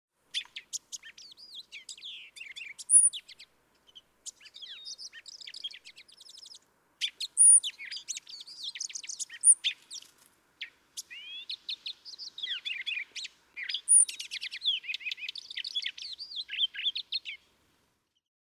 Cassin’s finch
♫95. Song of a male apparently courting a female, muted, punctuated with high, intense notes. Listen for the mimicry of the northern flicker (0:04, 0:12) and ruby-crowned kinglet (0:02, 0:12).
Old Faithful, Yellowstone National Park, Wyoming.
095_Cassin's_Finch.mp3